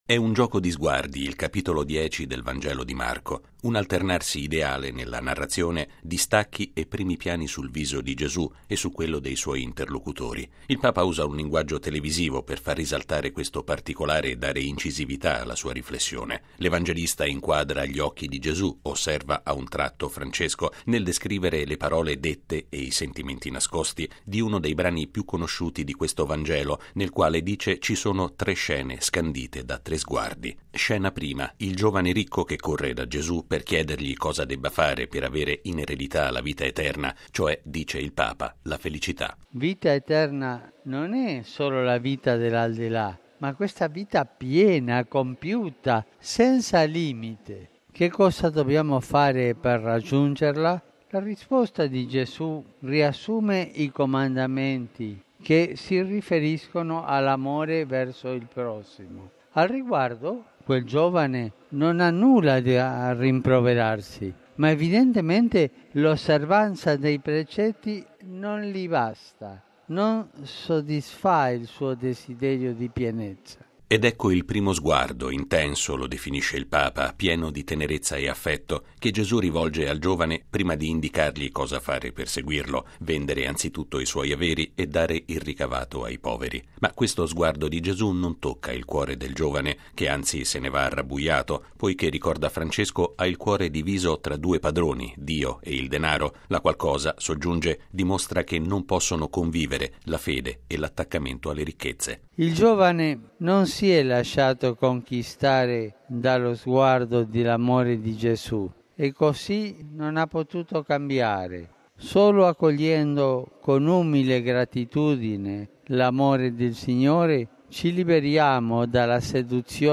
Papa Francesco ha espresso questa convinzione commentando all’Angelus il Vangelo della liturgia domenicale e concludendo con un nuovo appello alla tutela dell’ambiente, in vista della prossima Giornata per la riduzione dei disastri naturali. Il servizio